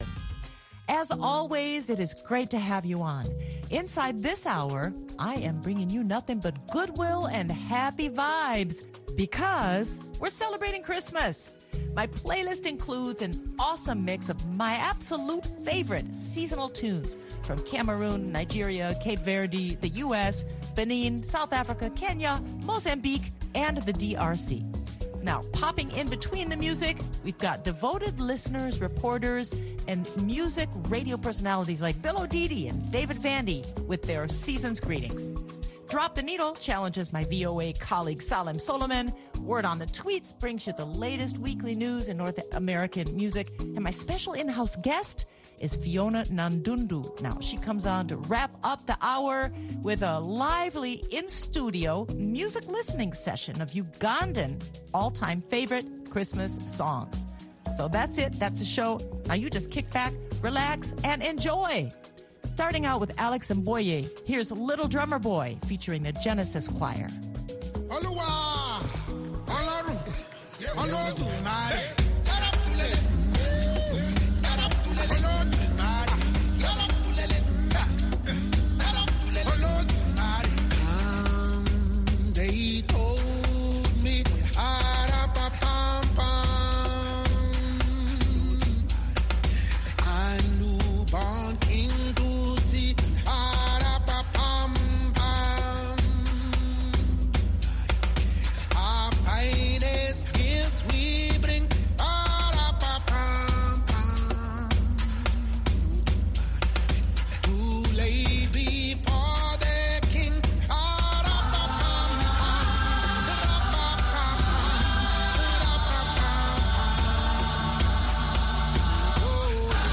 African Christmas music and a few American seasonal hits spin this week's playlist.